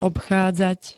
obchádzať [-(d)zať], obchodiť ndk
Zvukové nahrávky niektorých slov